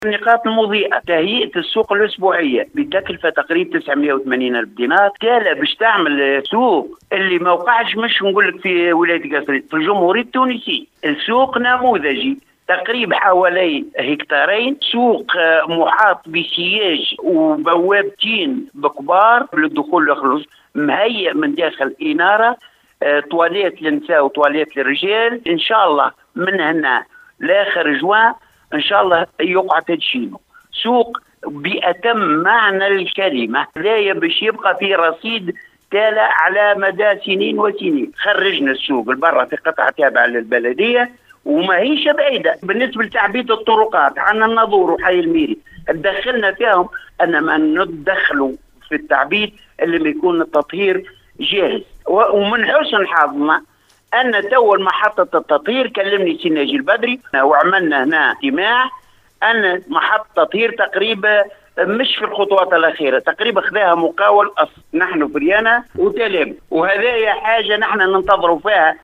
تشهد معتمدية تالة انفراجا في إنجاز بعض المشاريع البلدية هذا ما أفادنا به رئيس البلدية عبد المجيد الحيوني أثناء تدخّله ببرنامج القصرين و أحوالها صباح اليوم الأربعاء 25 ماي 2022 ، و من بين هذه المشاريع تهيئة السوق الأسبوعيّة بتكلفة تناهز 980 ألف دينار .